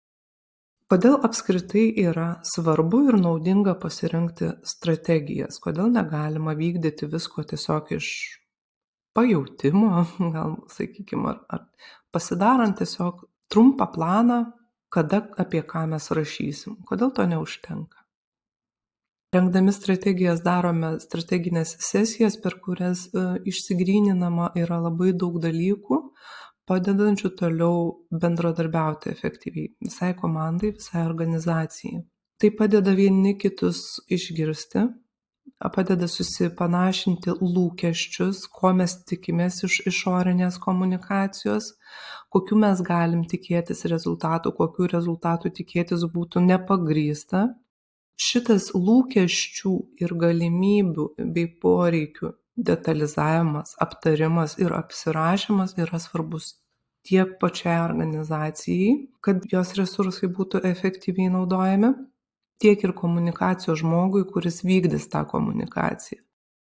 Komunikacijos strategė